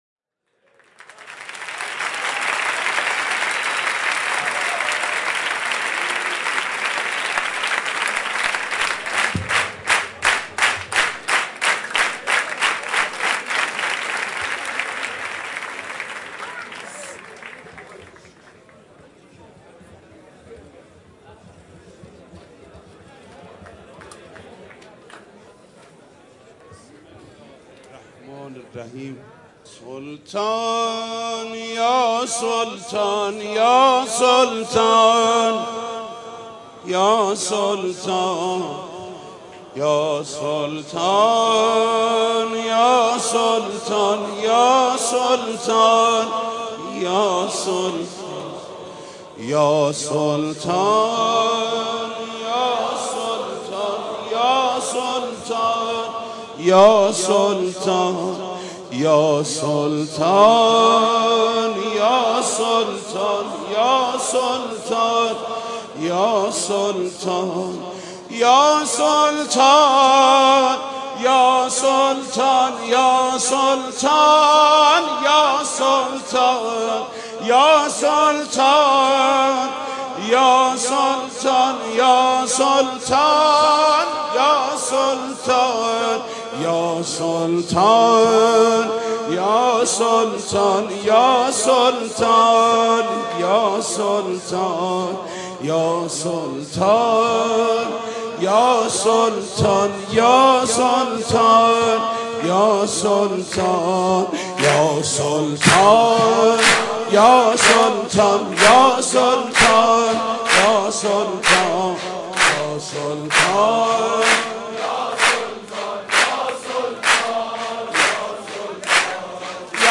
«میلاد امام رضا 1393» سرود: یا سلطان یا سلطان